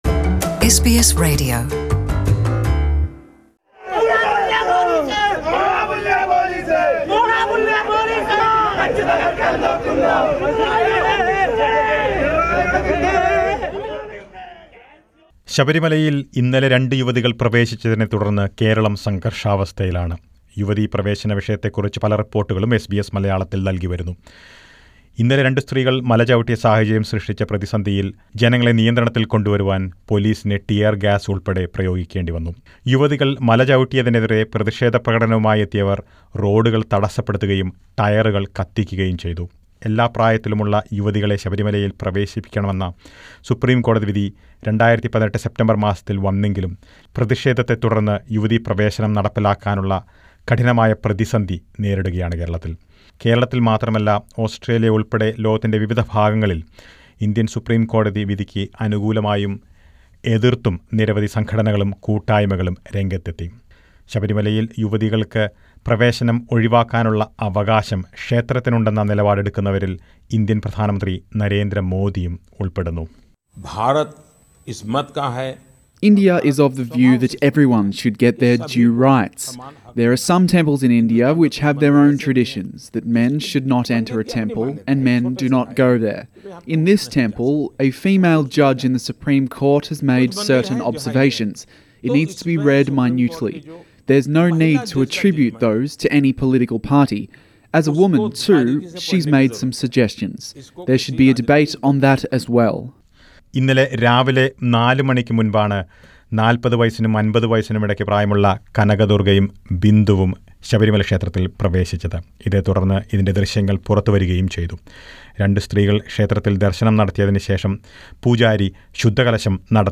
Listen to a report on the opinions of a few Australian Malayalees regarding the recent women entry in Sabarimala temple.